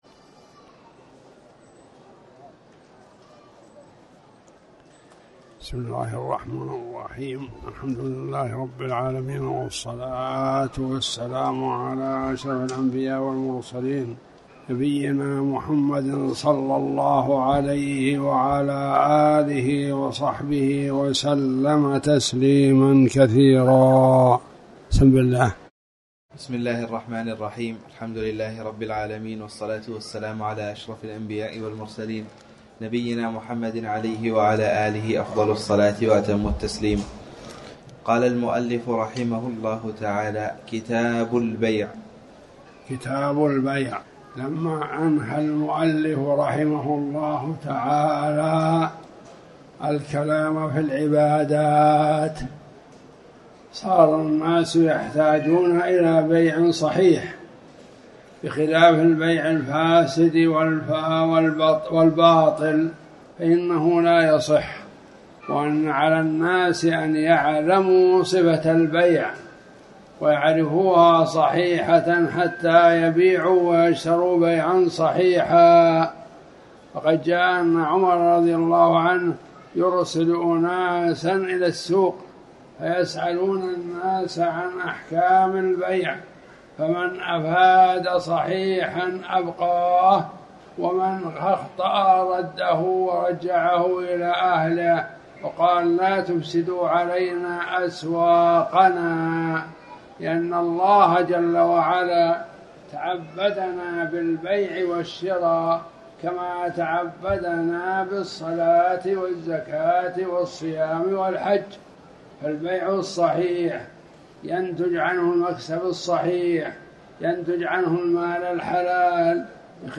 تاريخ النشر ٢٩ ربيع الأول ١٤٤٠ هـ المكان: المسجد الحرام الشيخ